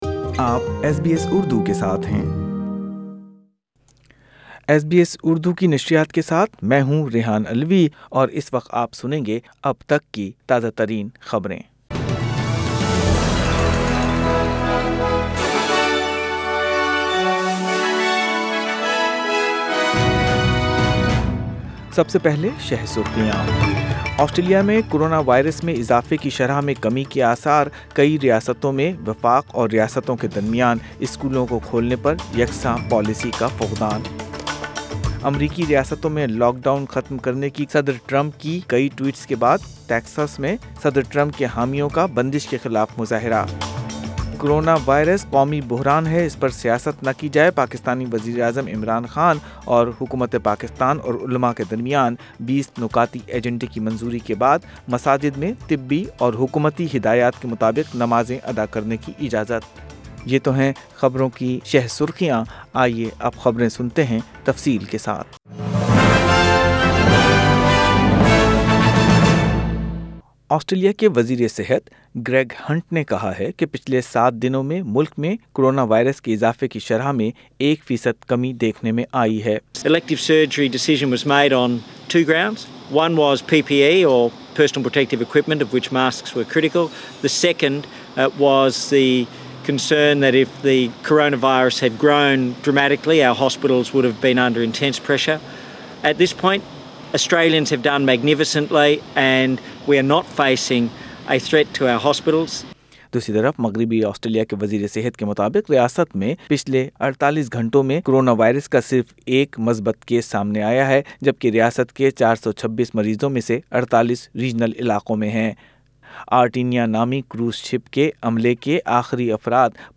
Urdu News 19th. April 2020
Listen detailed News in Urdu - 19th. April 2020